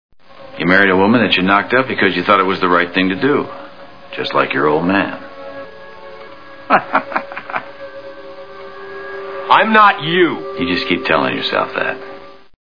Six Feet Under TV Show Sound Bites